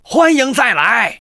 文件 文件历史 文件用途 全域文件用途 Rt_fw_02.ogg （Ogg Vorbis声音文件，长度1.2秒，127 kbps，文件大小：19 KB） 源地址:游戏语音 文件历史 点击某个日期/时间查看对应时刻的文件。 日期/时间 缩略图 大小 用户 备注 当前 2018年5月20日 (日) 14:52 1.2秒 （19 KB） 地下城与勇士  （ 留言 | 贡献 ） 分类:诺顿·马西莫格 分类:地下城与勇士 源地址:游戏语音 您不可以覆盖此文件。